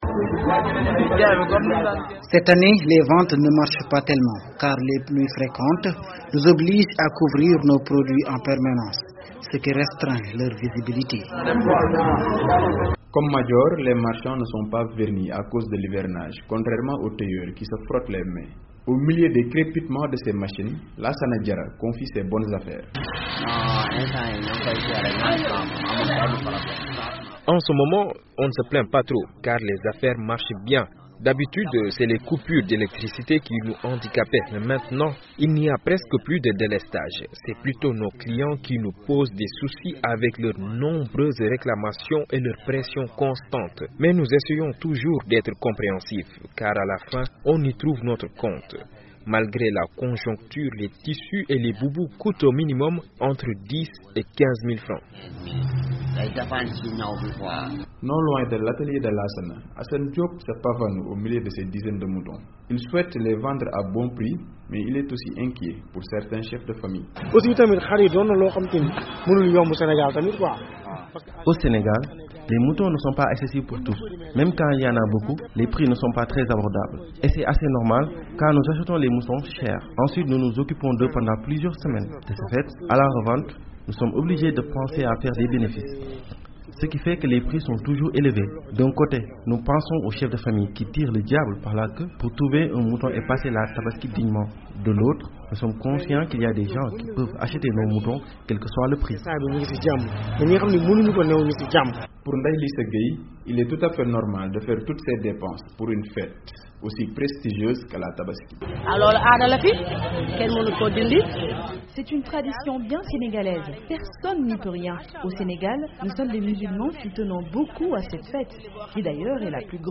L'achat du mouton continue d’être un casse-tête pour les ménages et les habits coûtent de plus en plus chers. L'ambiance bat son plein au marché Hlm, en plein cœur de Dakar.
Reportage